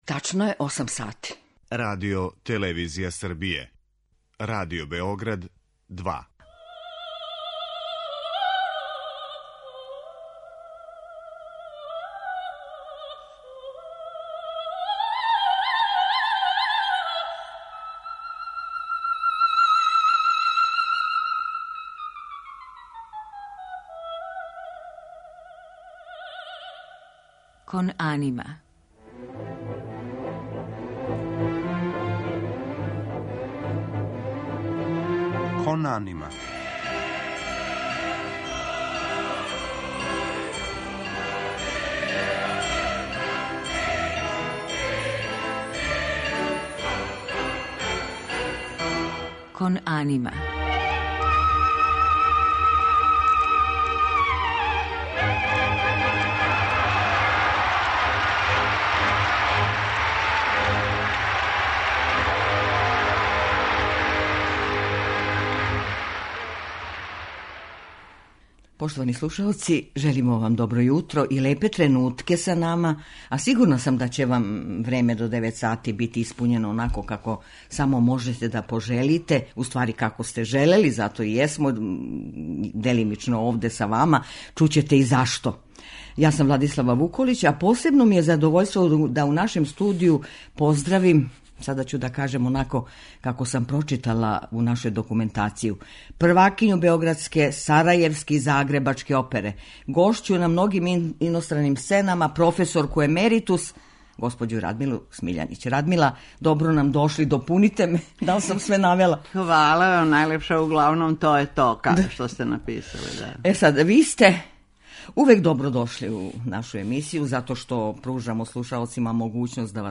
Гошћа - Радмила Смиљанић
Тим поводом, гошћа у емисији Кон анима је наша позната вокална уметница и дугогодишња првакиња сарајевске, али и загребачке и, наравно, београдске опере, професорка емеритус Радмила Смиљанић, која је позвана да учествује на концертима у Сарајеву.